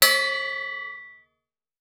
Boxing Bell Short Ring.wav